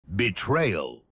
Play Halo Reach Betrayal Voice - SoundBoardGuy
Play, download and share Halo reach betrayal voice original sound button!!!!
halo-reach-betrayal-voice.mp3